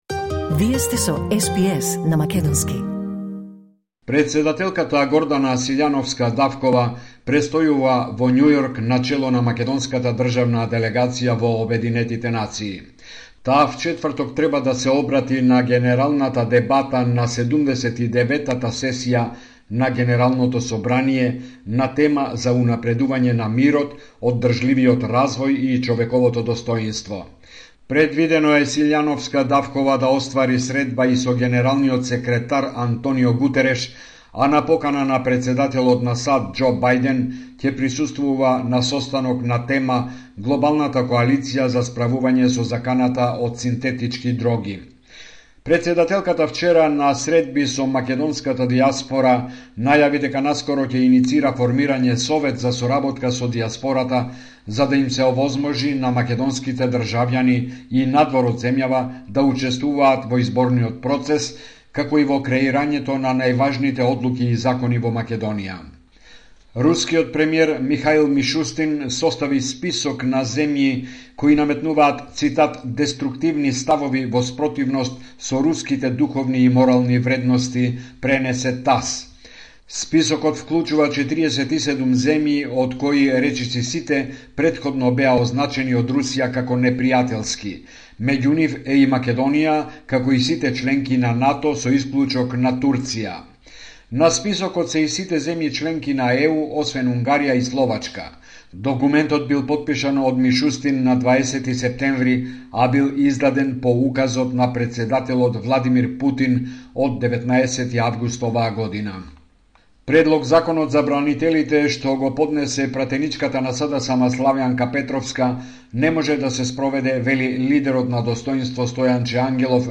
Извештај од Македонија 23 септември 2024